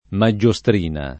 vai all'elenco alfabetico delle voci ingrandisci il carattere 100% rimpicciolisci il carattere stampa invia tramite posta elettronica codividi su Facebook maggiostrina [ ma JJ o S tr & na ] o magiostrina s. f. — voce lomb. per «paglietta»